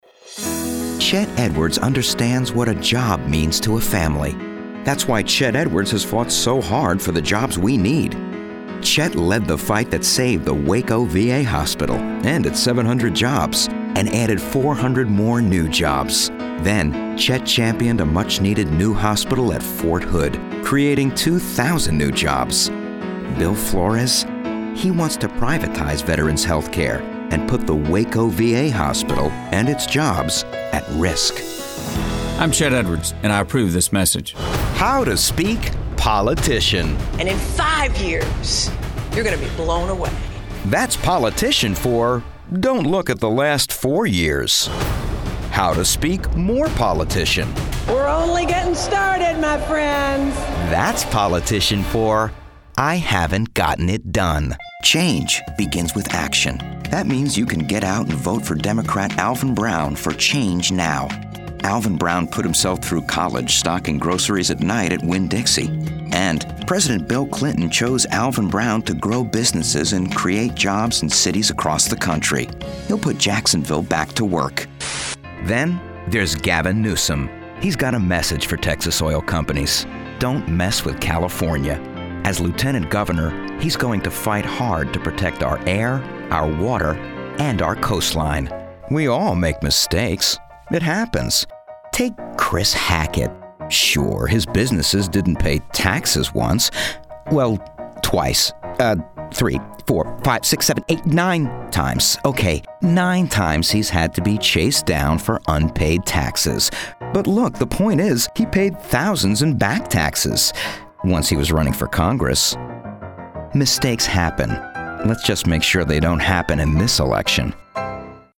Male VOs